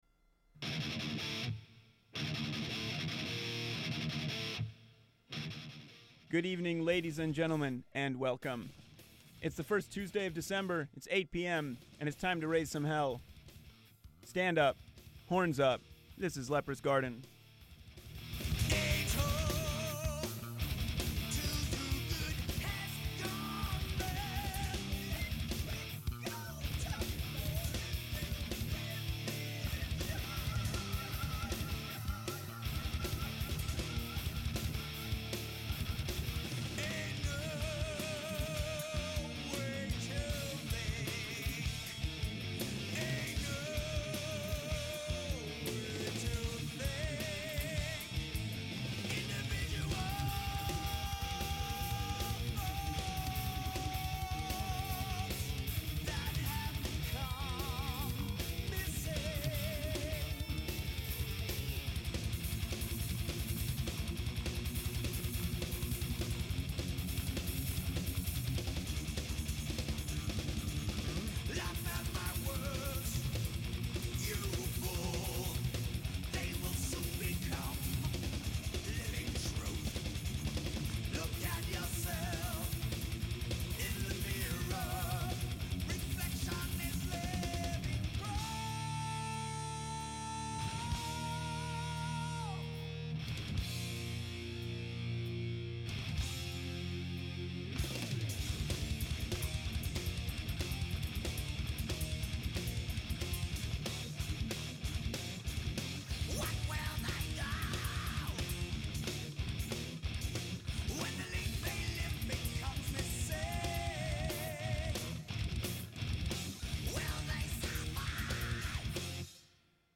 Broadcast on WGXC (90.7-FM) and Standing Wave Radio (1620-AM).